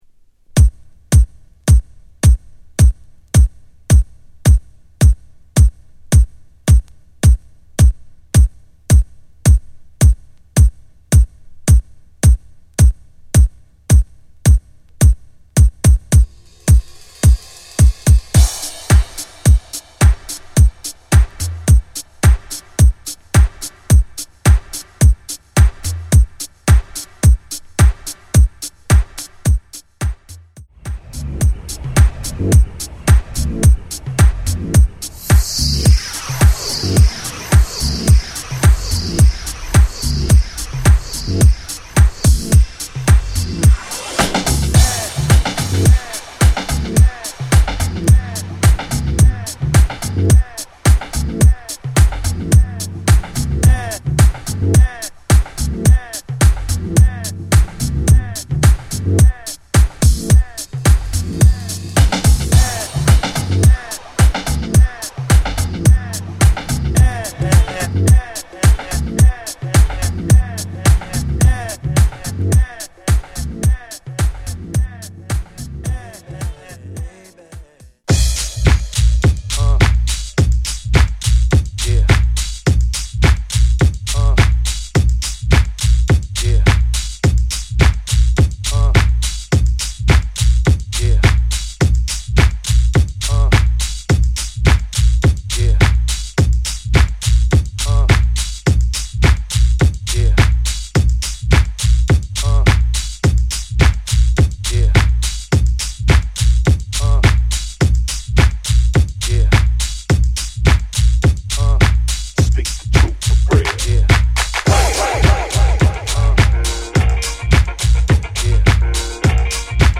808風のチープなパーカスやハンドクラップをダビーなトラックへ落とし込んだ